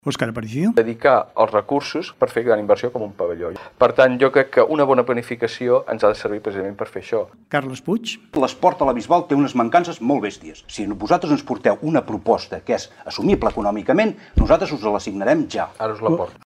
Debat Electoral la Bisbal 2019
Com no podia ser d’altra manera Ràdio Capital ha emès el col·loqui.